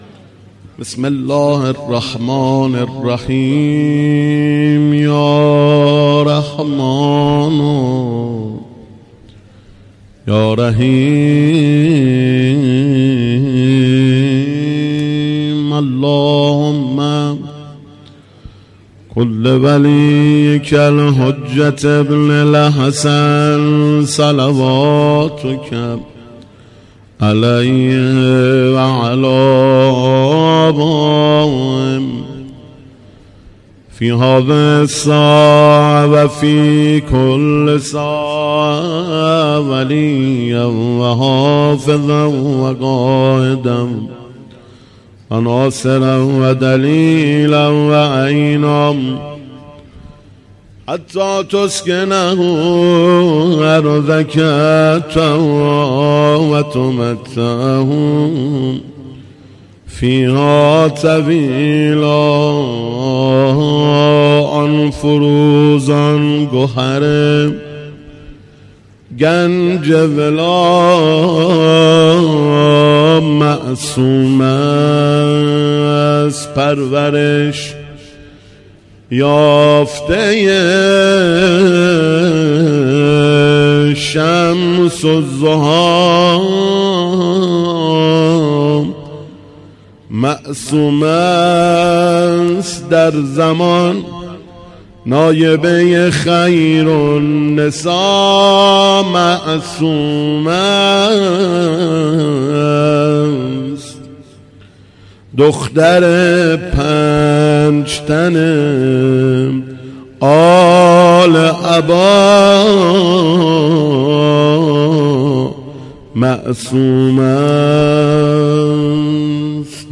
مداحی روز میلاد حضرت فاطمه معصومه -سلام الله علیها